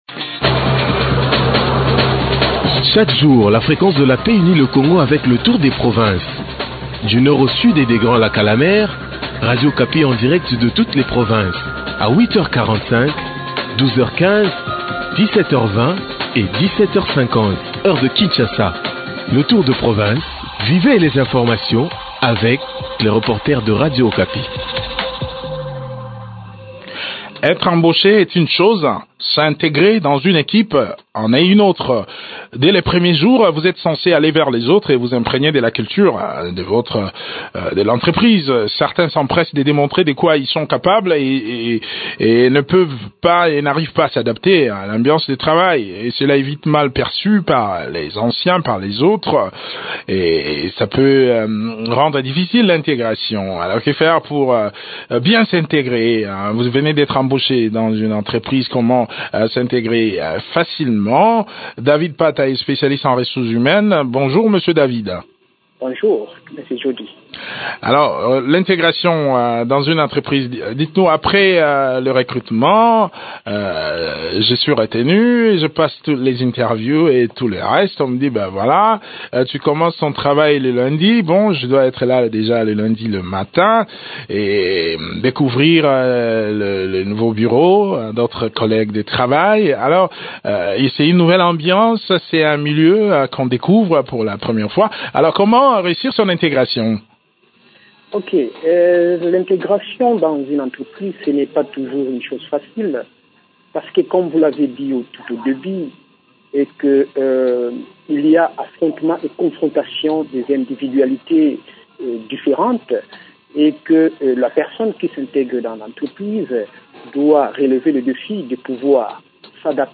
expert en ressources humaines.